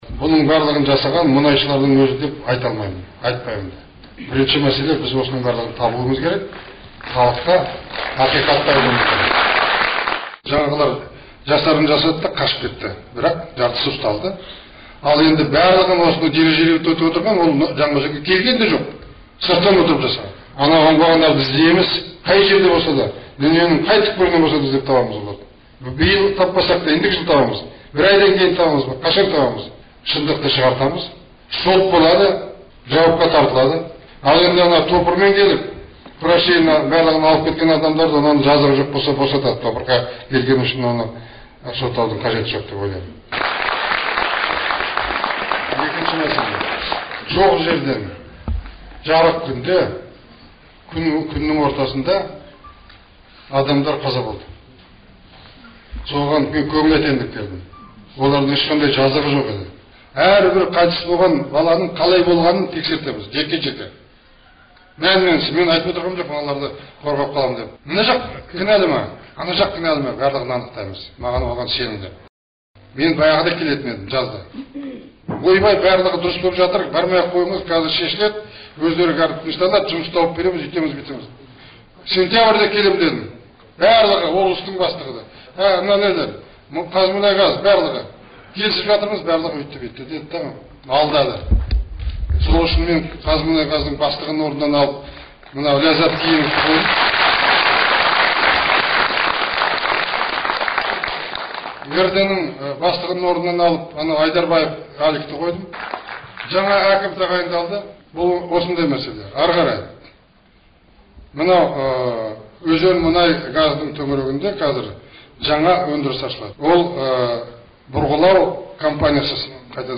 Желтоқсанның 22-і күні Қазақстан президенті Нұрсұлтан Назарбаев Маңғыстау облысына сапарында жергілікті органдар өкілдері және мәслихат депутаттарымен кездесуде Жаңаөзенде болған оқиға туралы пікір айтты.